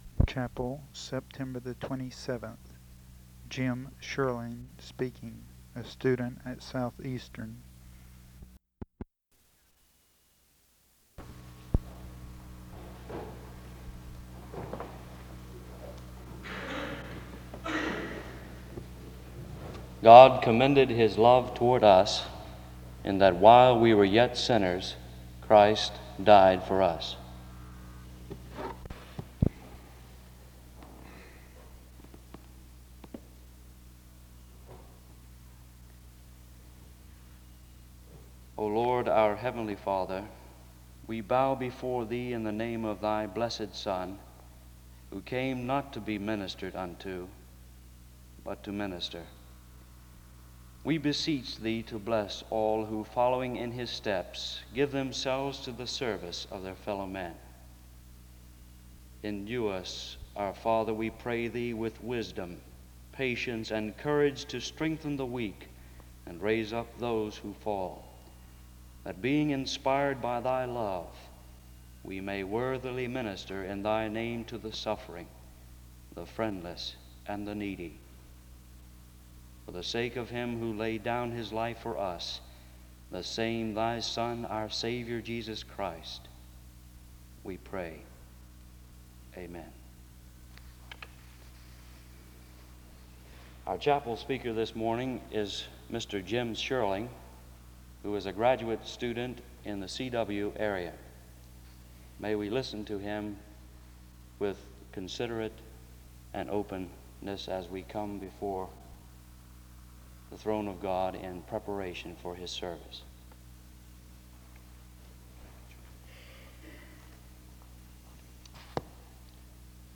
The service begins with a prayer and introduction to the speaker from 0:19-1:53.
This service was organized by the Student Coordinating Council.